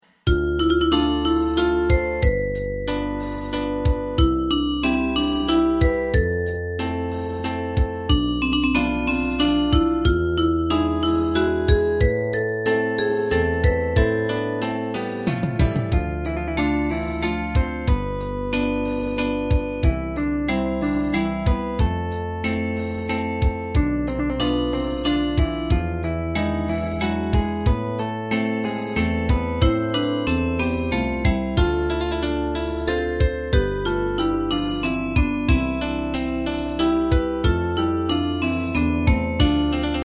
大正琴の「楽譜、練習用の音」データのセットをダウンロードで『すぐに』お届け！
カテゴリー: アンサンブル（合奏） .